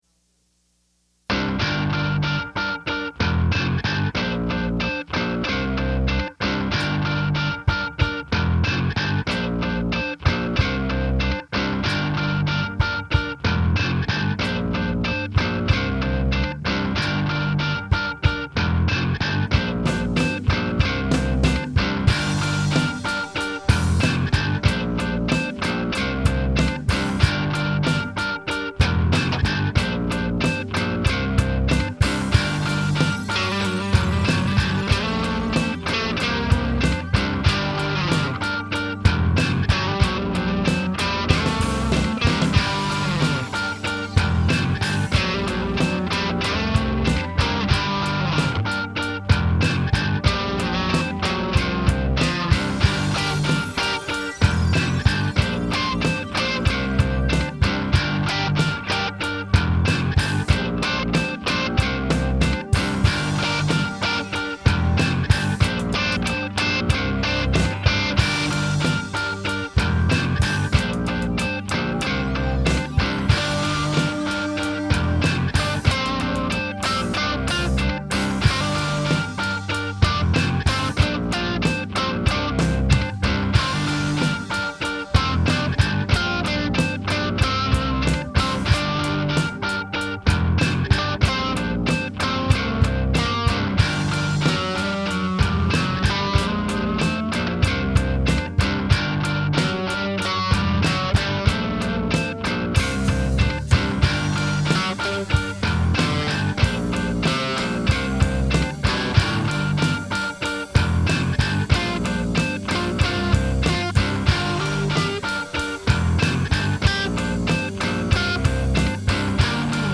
This piece features a guitar loop with a separate lead played over top.
Some electric piano and a synth patch – and now we have favorable atmospheric conditions.